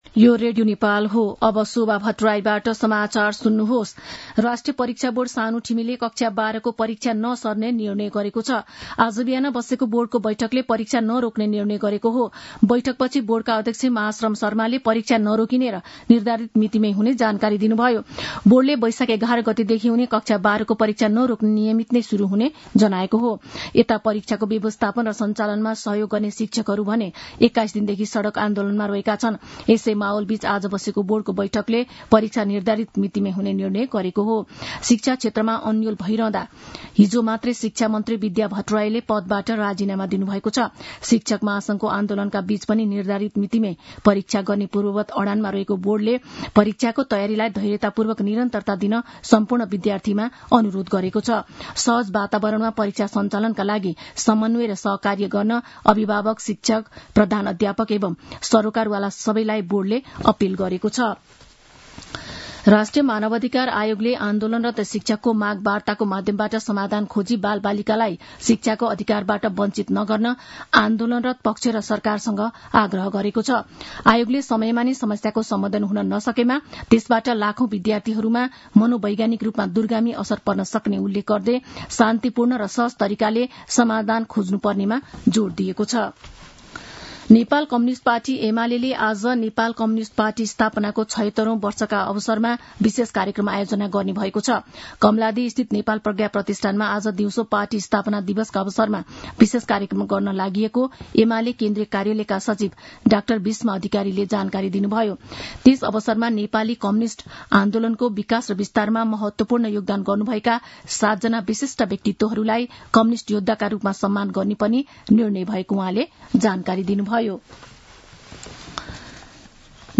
मध्यान्ह १२ बजेको नेपाली समाचार : ९ वैशाख , २०८२